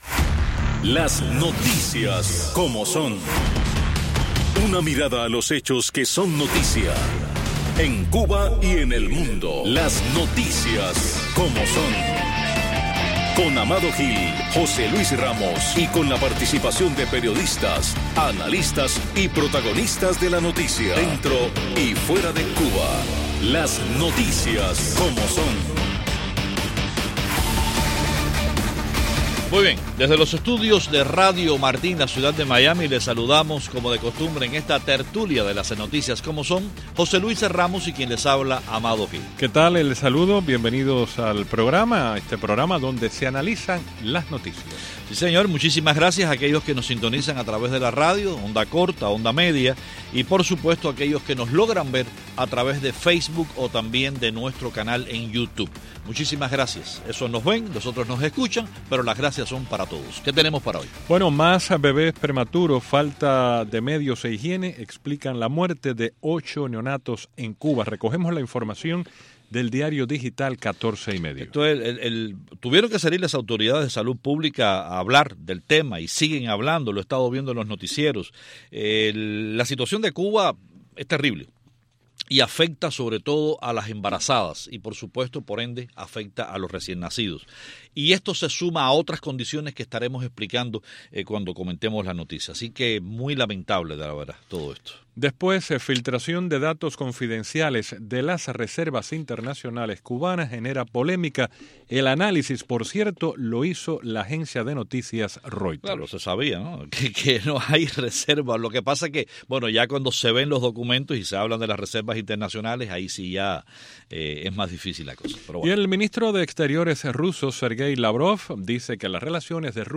En la Tertulia de Las Noticias Como Son: 🔷 Más bebés prematuros, falta de medios e higiene explican la muerte de ocho neonatos en Cuba 🔷 Filtración de datos confidenciales de las reservas internacionales cubanas genera polémica.